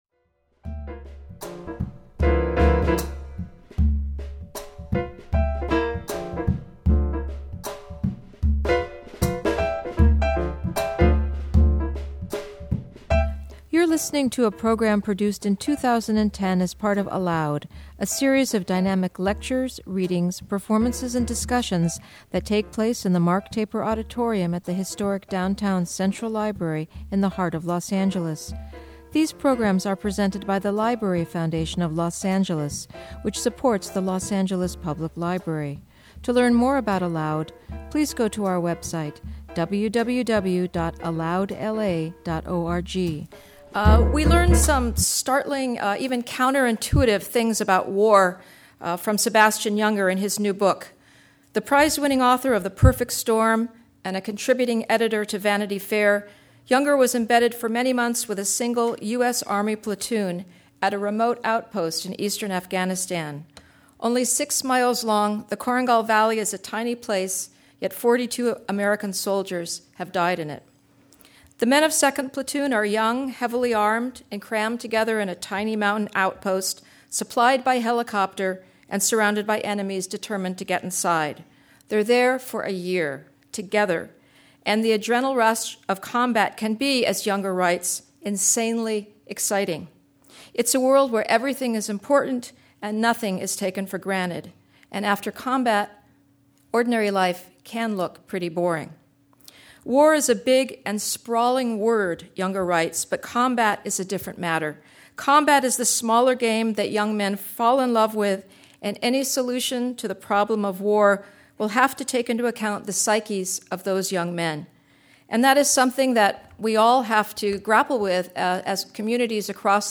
In conversation with Writer/Director John Sacret Young